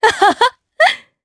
Seria-Vox_Happy3_jp.wav